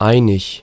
Ääntäminen
GenAm: IPA : /ˈplɛn.ti/